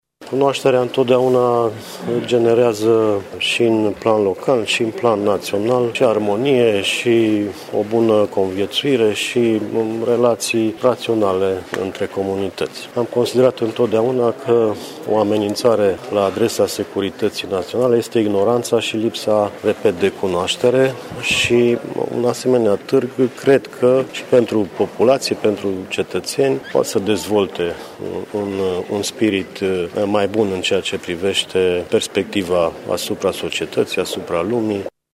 Asta spune fostul şef al Serviciului Român de Infromaţii, George Cristian Maior, care a fost prezent astăzi la Tîrgul de Carte Bookfest Tîrgu-Mureş.